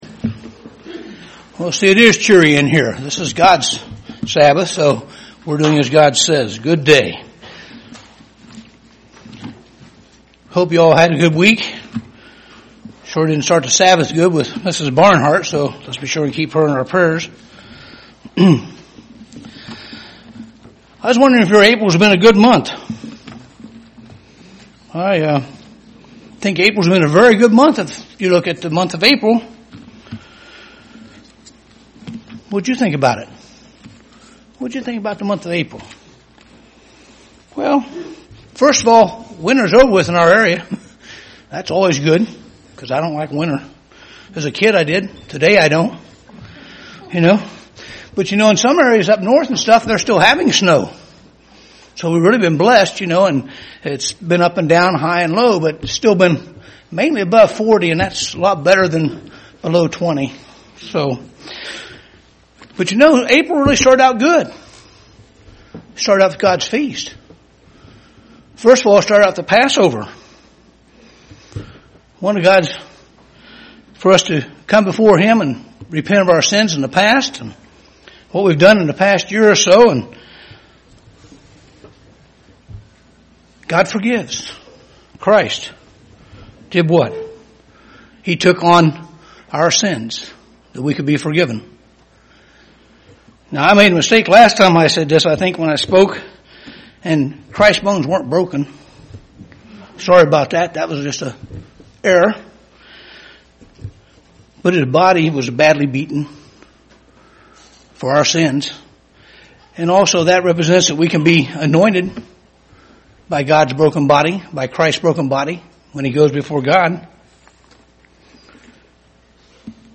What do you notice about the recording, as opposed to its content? Given in Dayton, OH